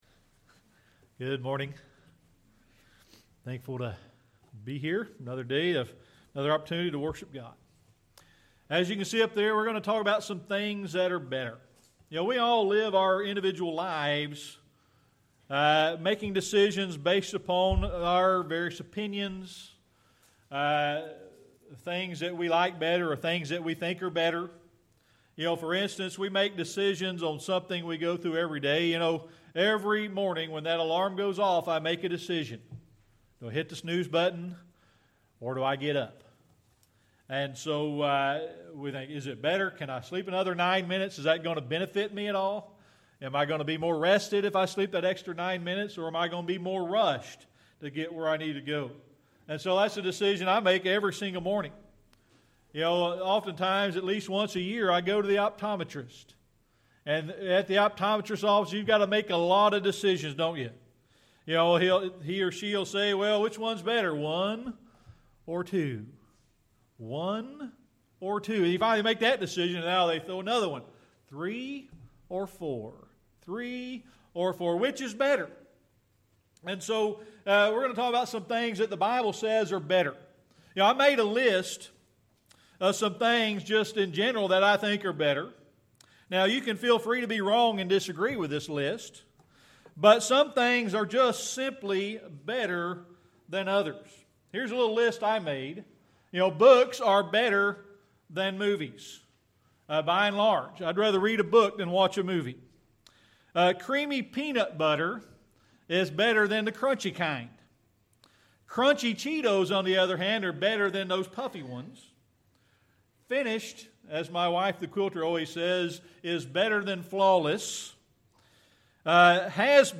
Psalm 118:8 Service Type: Sunday Morning Worship We're going to talk about some things that are better.